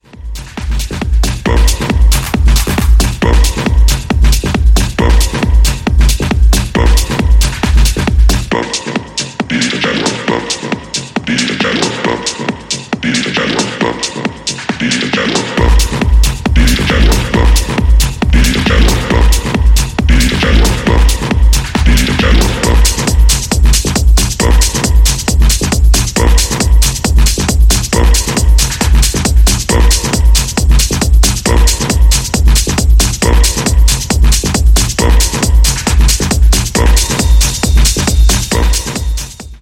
ラフなパワーを持ったミニマル・チューン
90sのUSテクノに通じるエネルギーで深い時間をハメるテクノ・トラックス